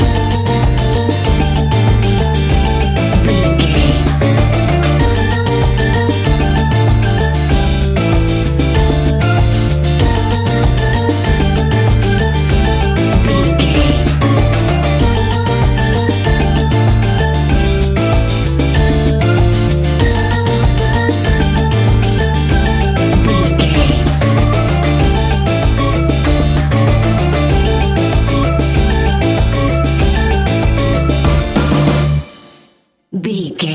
Aeolian/Minor
steelpan
worldbeat
percussion
brass
guitar